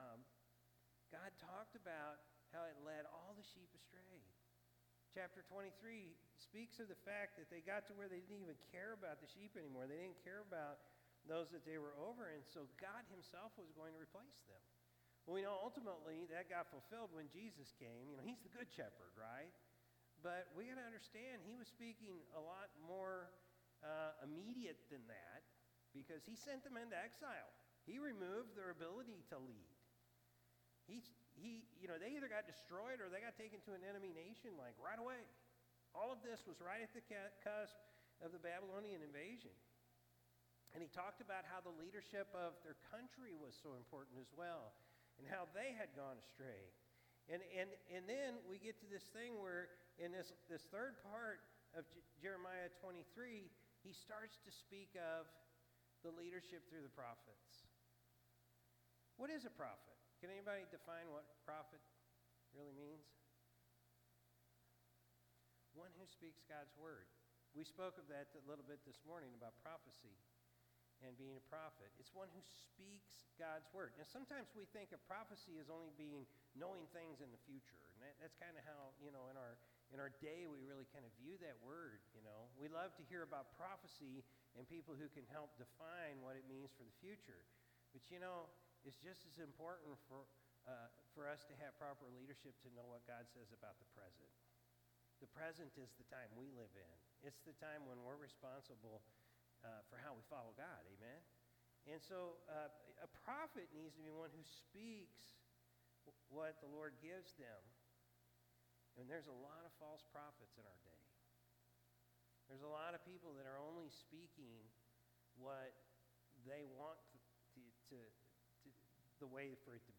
November-3-2024-Evening-Service.mp3